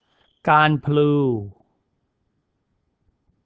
Garn-ploo.